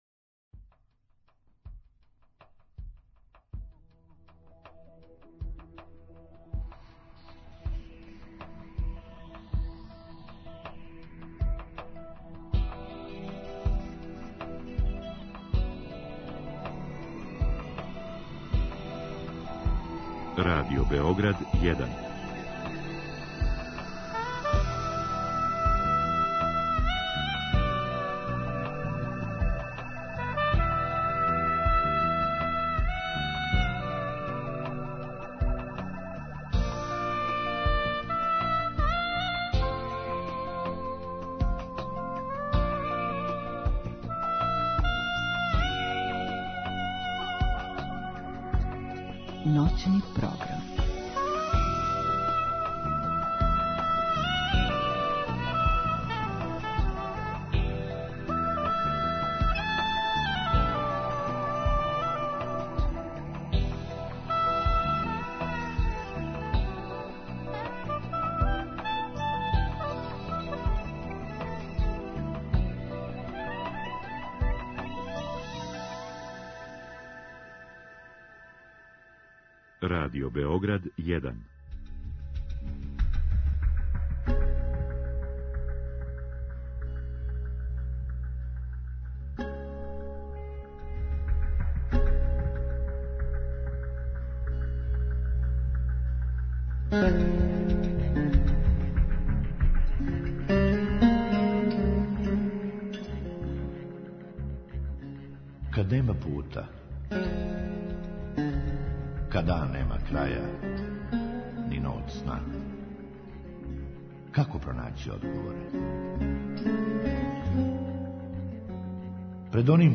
У другом сату слушаоци у директном програму могу поставити питање гошћи.